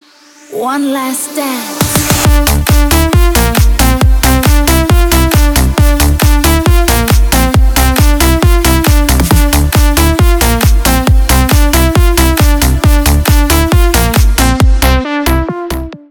Электроника
клубные # громкие